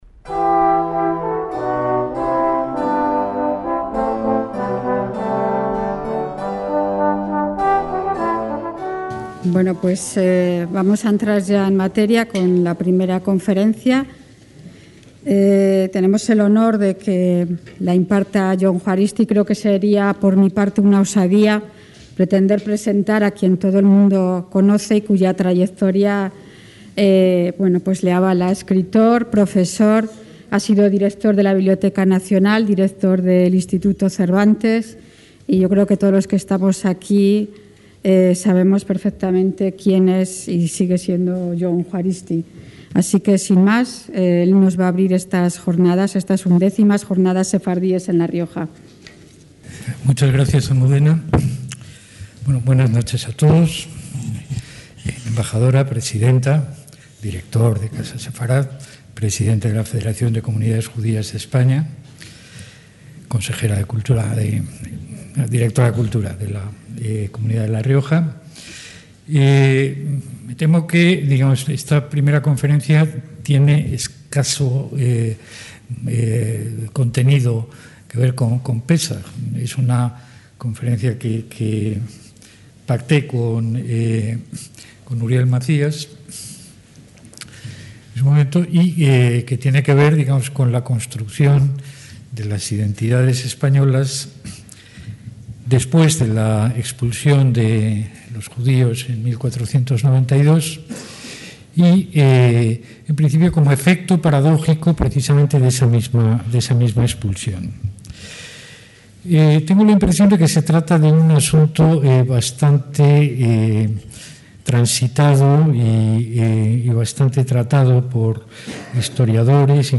JORNADAS SEFARDÍES EN SAN MILLÁN DE LA COGOLLA - Jon Juaristi (Universidad de Alcalá de Henares, exdirector de la Biblioteca Nacional de España y del Instituto Cervantes) fue el encargado de la conferencia inaugural de la XIª edición de estas Jornadas con la charla: La interminable presencia de los expulsados: los avatares del judío en la España del Antiguo Régimen.